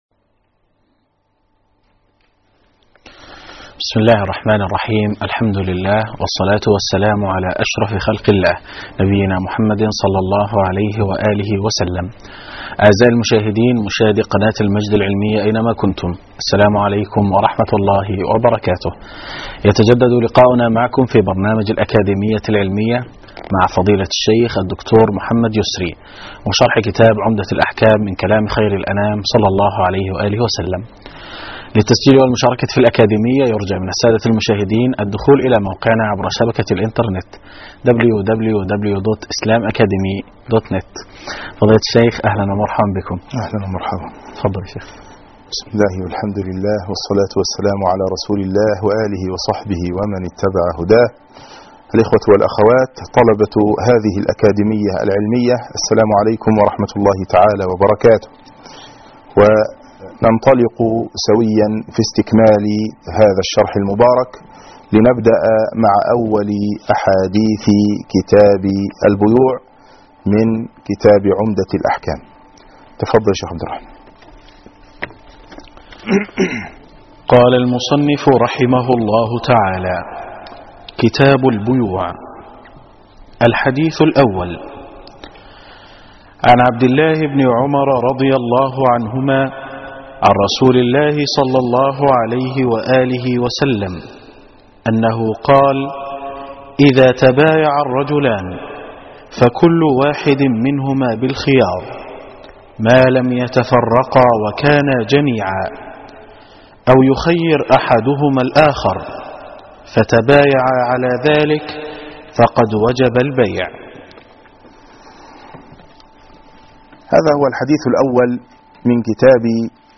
الدرس الثالث _ الحديث الأول